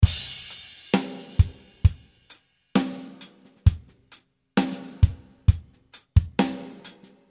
66 bpm slow and romantic drum beat loops
66 bpm slow and romantic rhythm.